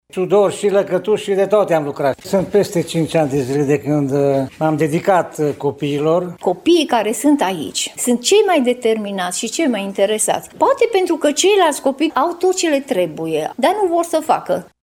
După ce în ultimii ani s-a investit în interior, acum s-a reabilitat și exteriorul cu 323 de mii de lei alocați de Consiliul Județean Mureș și Primăria Târnăveni. Centrul n-ar putea funcționa fără voluntari din Târnăveni care le oferă copiilor timpul și experiența lor: